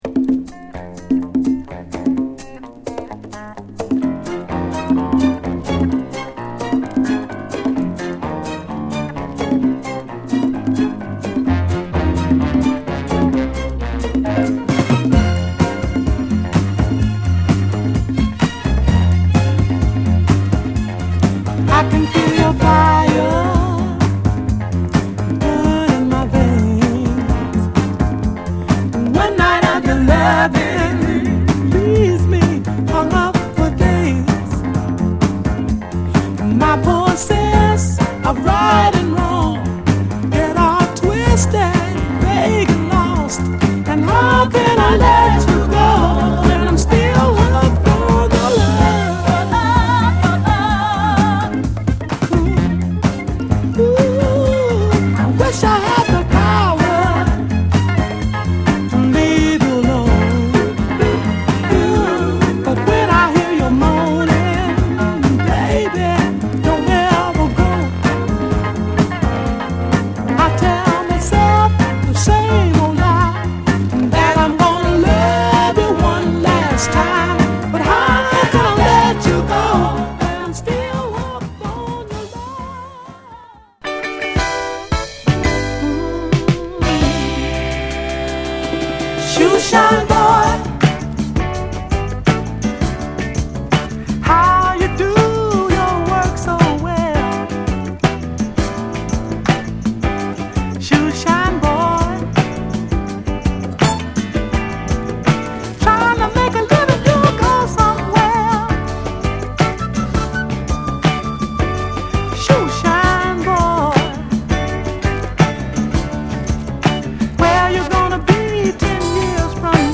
7inch
スリリングなダンスチューン&心地良いメロウミディアムのナイスカップリング！...